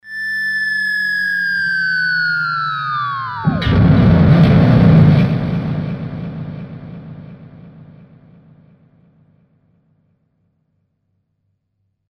Bomba   mp3 12'' 190 Kb
bomba.mp3